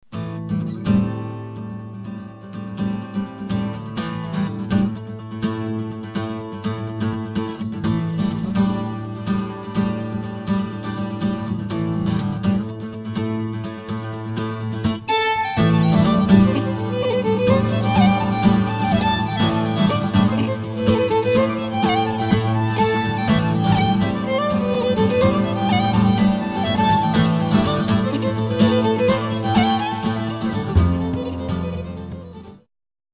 Fiddle, Banjo, Guitar, Percussion, Bass.
Opening with a stirring set of reels